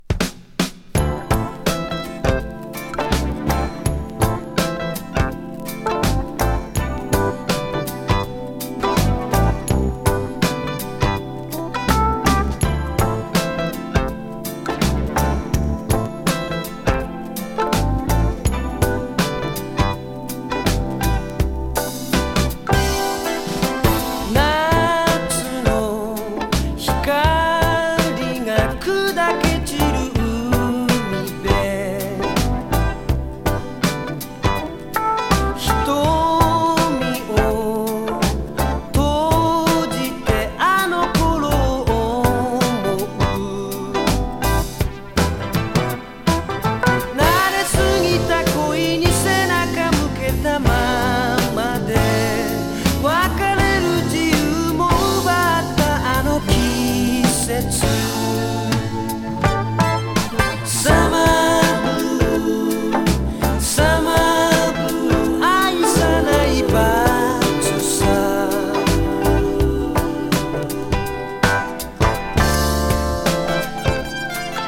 ドラマチック・ディスコ歌謡のタイトル曲